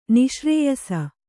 ♪ niśrēyasa